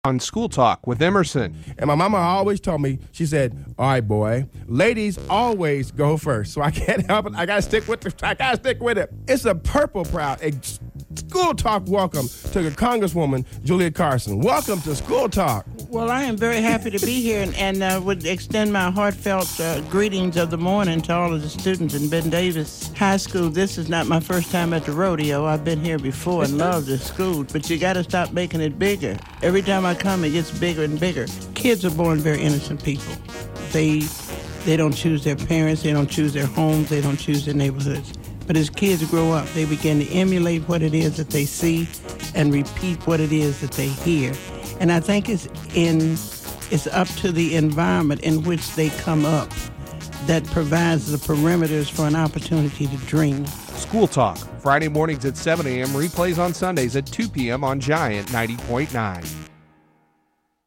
Congresswoman Julia Carson appeared on School Talk in 2004 as we held a Candidates' Forum.